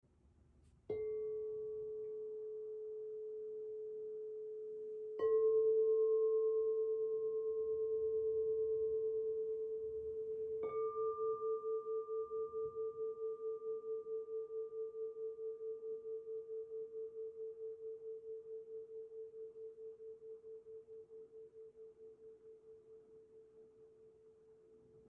All three of these notes are A… but they’re not quite the same. 🎵 A at 432 Hz 🎵 A at 440 Hz 🎵 A at 444 Hz They sound similar, but those subtle differences can totally shift how you feel. 🧠✨ Listen closely to the 440 Hz + 444 Hz together — that gentle pulse you hear? That’s a theta acoustic beat, thanks to the 4 Hz difference.